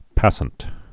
(păsənt)